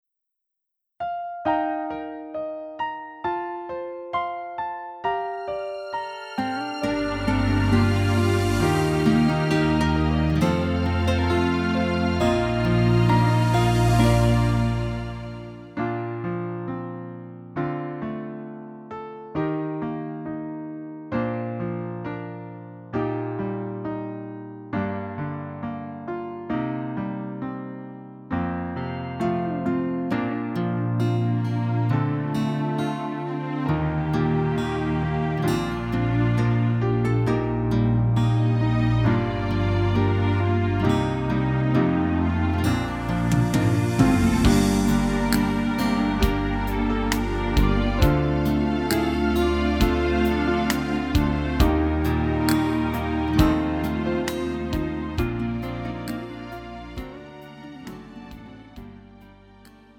음정 원키 4:34
장르 가요 구분 Lite MR